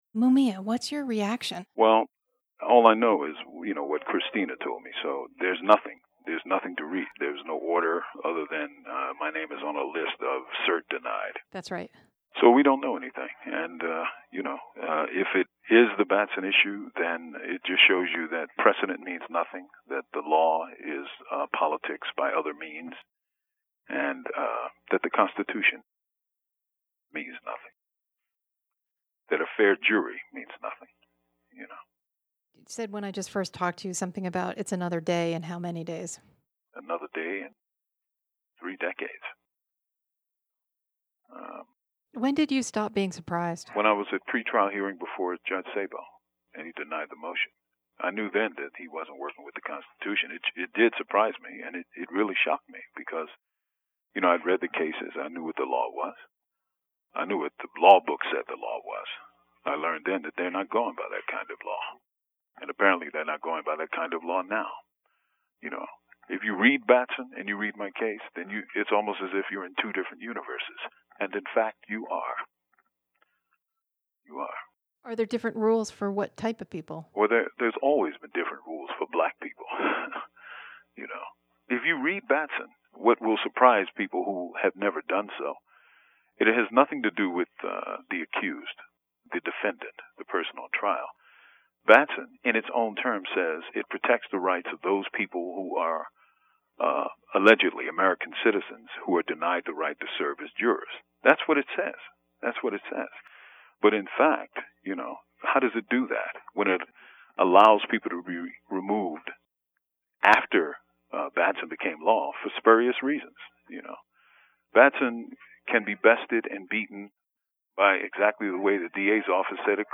(THE ATTACHED AUDIO FILE IS THE INTERVIEW WITH MUMIA CONDUCTED THIS MORNING BY PRISON RADIO)